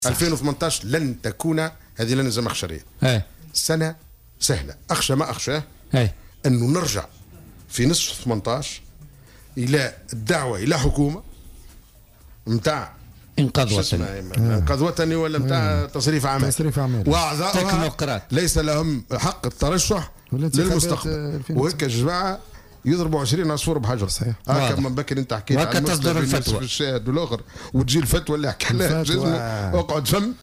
قال الوزير السابق ومؤسس مبادرة اليسار الكبير عبيد البريكي، خلال استضافته في برنامج "بوليتيكا" اليوم الخميس 28 ديسمبر 2017، إن سنة 2018 لن تكون سهلة أبدا.